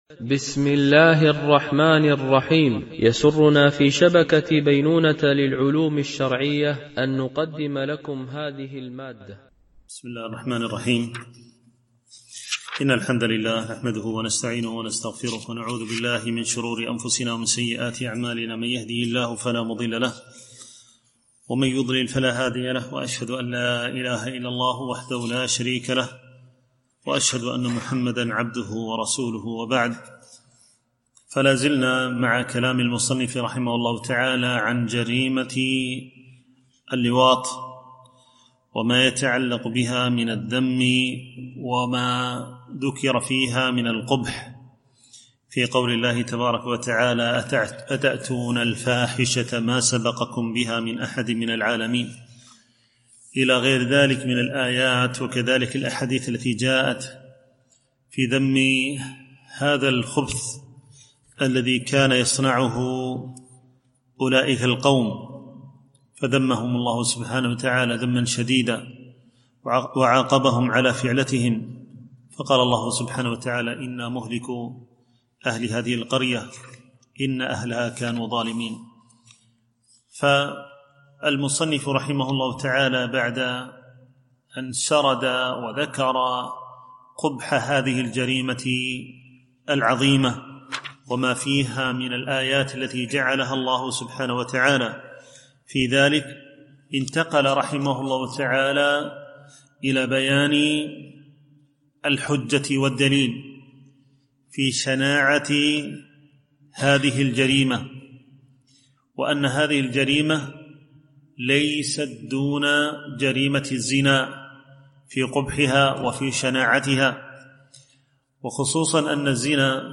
شرح كتاب الداء والدواء ـ الدرس 44